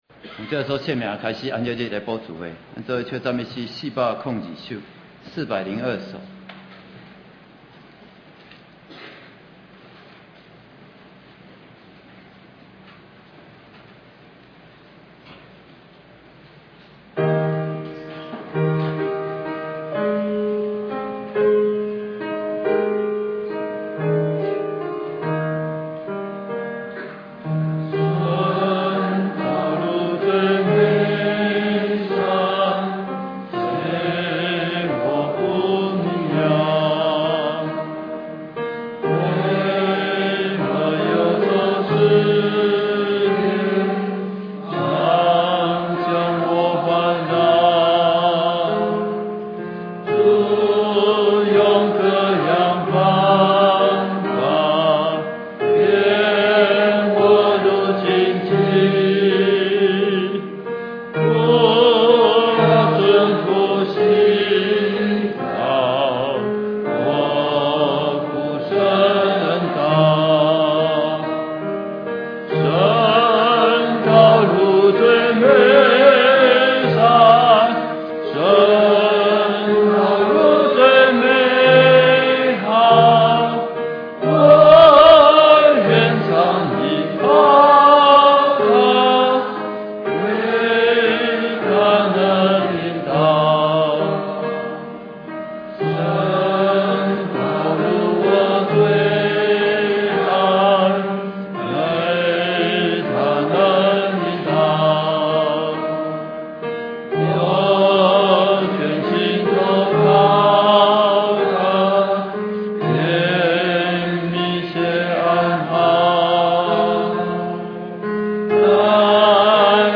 2016年宗教教育月專題講道